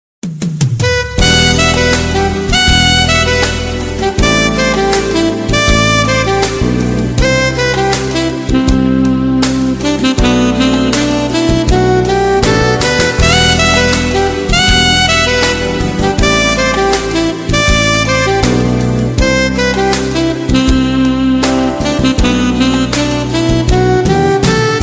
Kategorien Klassische